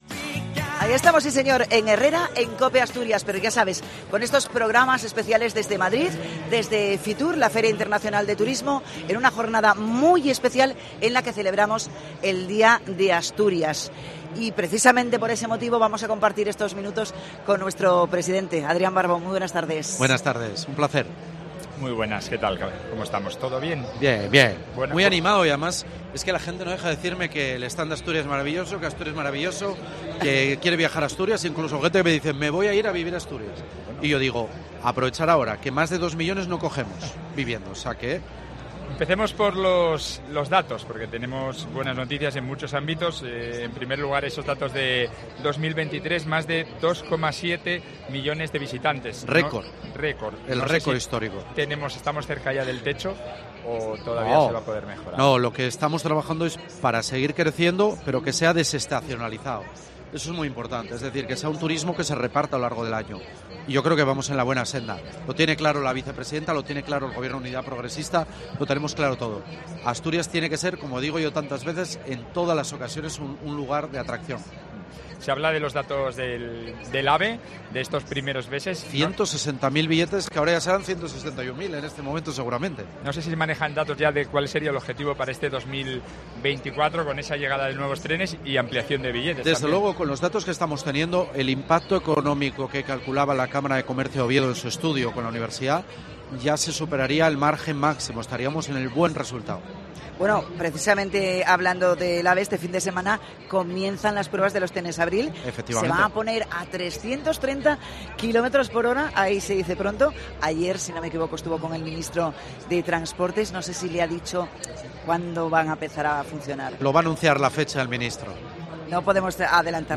FITUR 2024: Entrevista a Adrián Barbón, presidente de Adrián Barbón
El presidente del Principado, Adrián Barbón, ha participado en el especial que COPE Asturias emite desde IFEMA con motivo de la Feria Internacional del Turismo (FITUR 2024), que celebra, este viernes, el Día de Asturias.